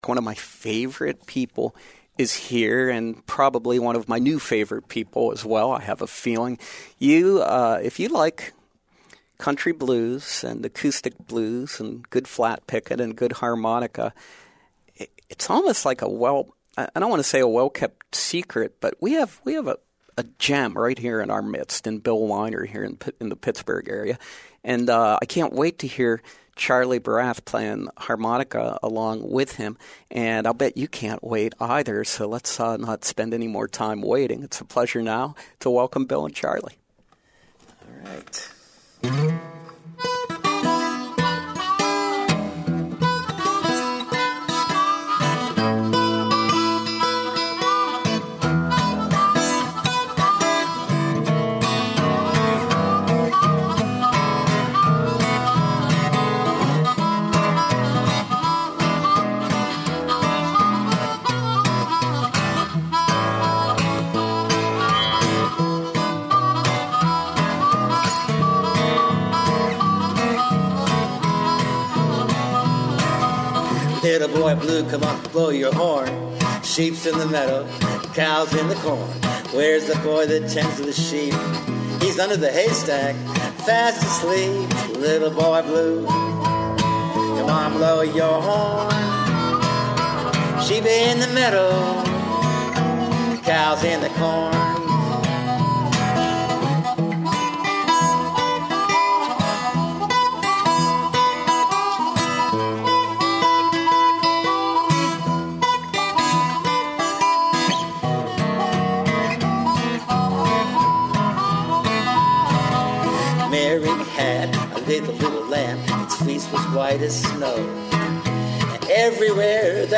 country-blues and old time folk guitar picker and singer
harp and vocals